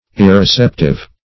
\ir`re*cep"tive\